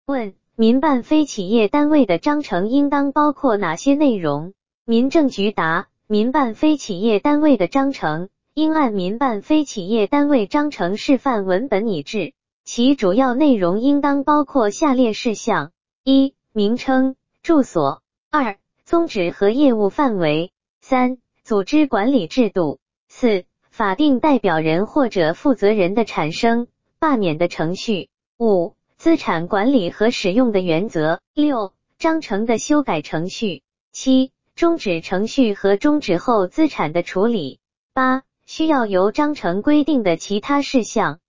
语音播报
语音合成中，请耐心等待...